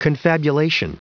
Prononciation du mot confabulation en anglais (fichier audio)